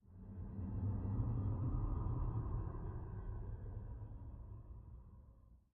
Minecraft Version Minecraft Version latest Latest Release | Latest Snapshot latest / assets / minecraft / sounds / ambient / nether / nether_wastes / dark1.ogg Compare With Compare With Latest Release | Latest Snapshot